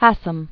(hăsəm), (Frederick) Childe 1859-1935.